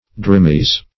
Drimys \Dri"mys\ (dr[imac]"m[i^]s), n. [NL., fr. Gr. drimy`s